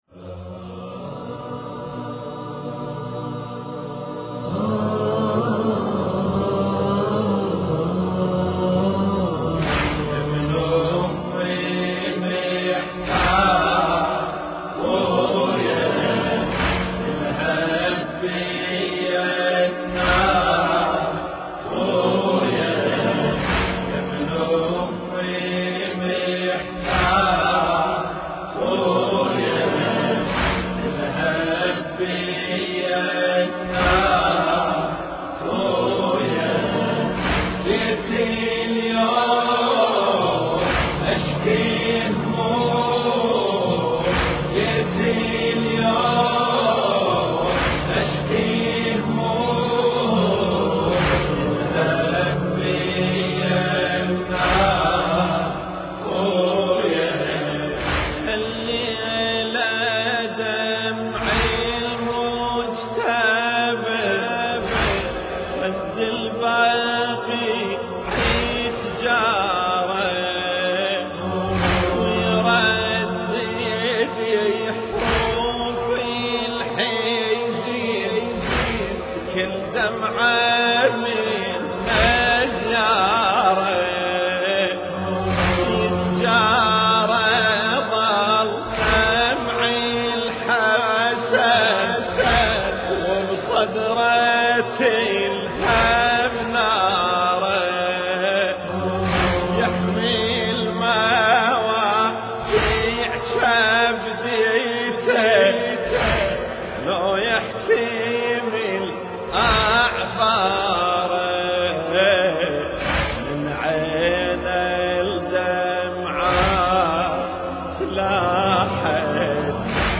مراثي الامام الحسن (ع)